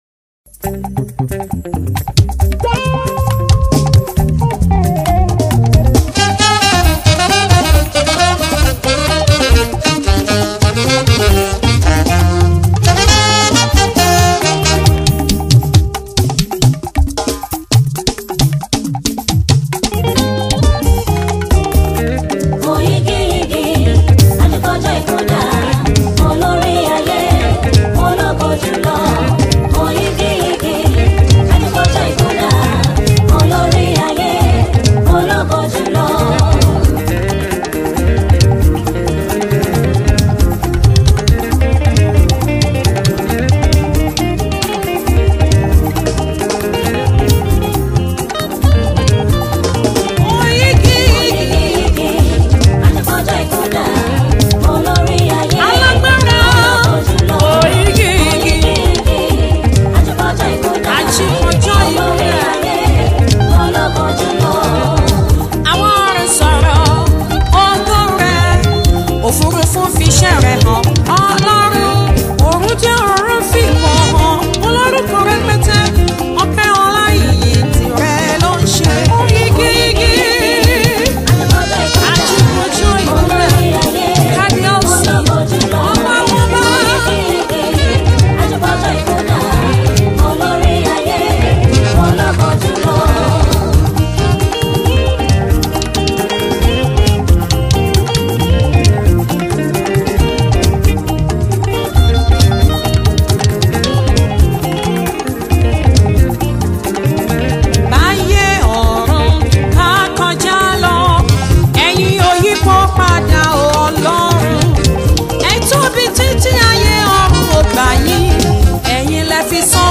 a praise medley song
gospel singer